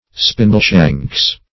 Spindleshanks \Spin"dle*shanks`\, n.